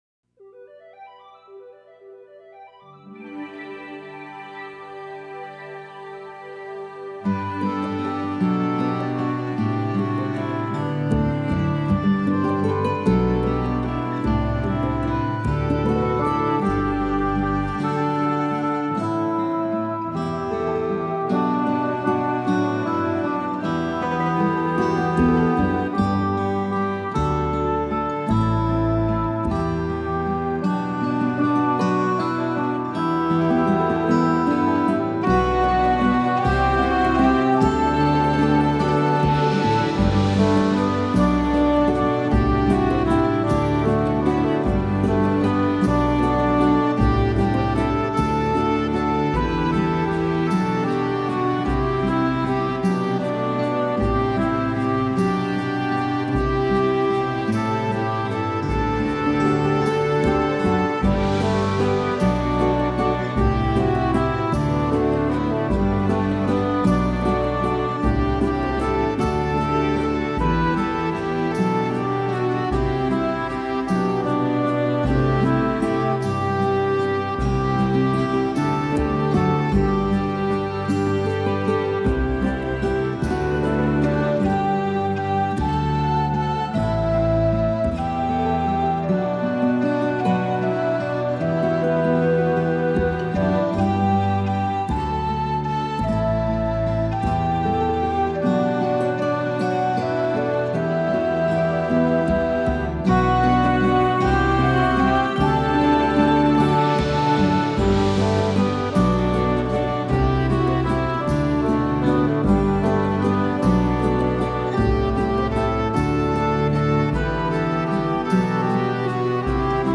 Meant to Me Instrumental 2012
meant-to-me-1-instrumental-lon.mp3